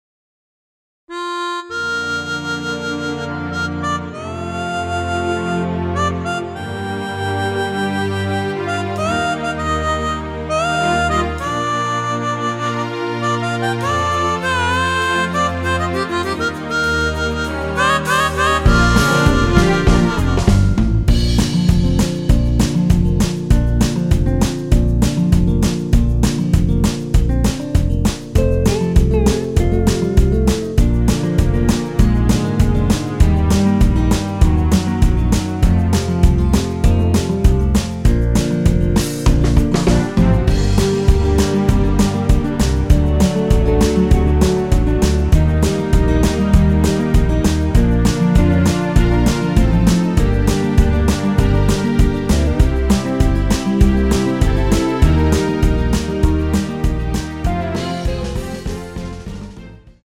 전주가 길어서 8마디로 편곡 하였으며
원키에서(-2)내린 (1절+후렴)으로 진행되는MR입니다.
Bb
앞부분30초, 뒷부분30초씩 편집해서 올려 드리고 있습니다.
중간에 음이 끈어지고 다시 나오는 이유는